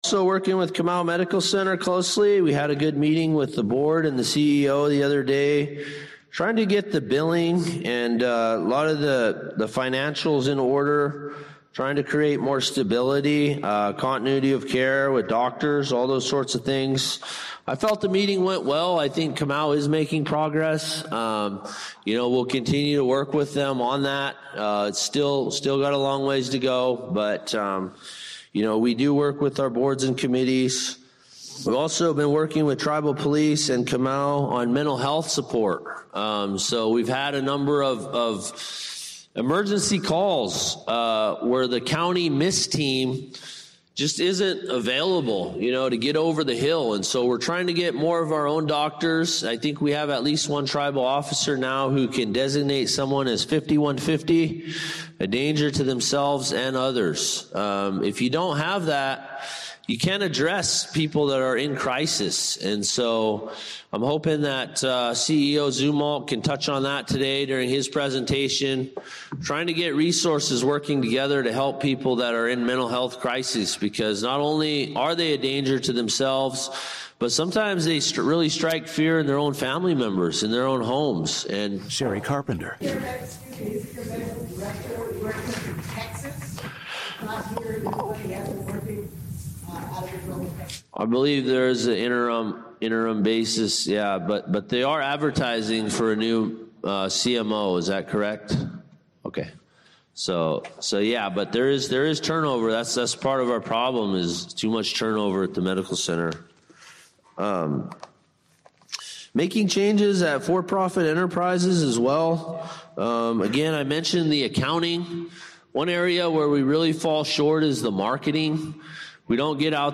This is the 2nd hour of the six-hour recording of the Hoopa Valley General Meeting of 2-21-26. In this segment we hear the continuance of Tribal Chairman’s Update. He then opens the floor to the listed Constitutional Amendments.